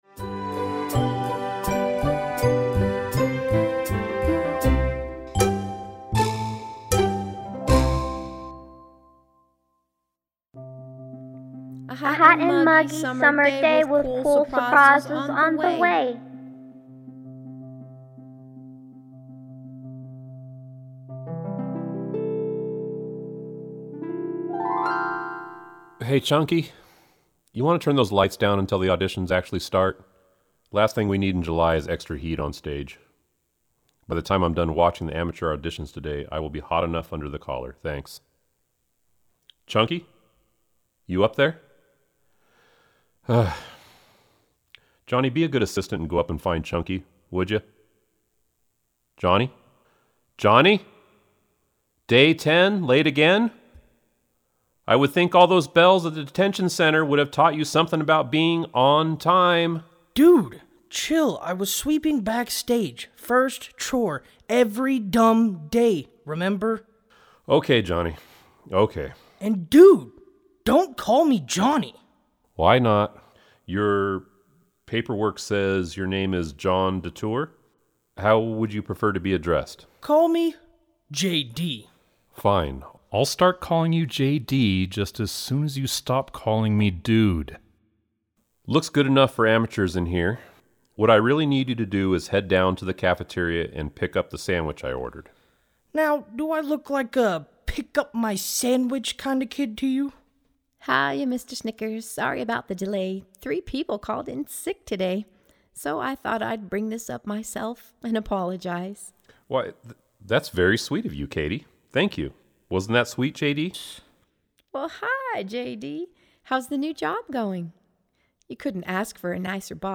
Sneak Preview –  Listen to these samples of the Audio Performances you will get with the Helpful Production Bundle for The Christmas Candy Auditions.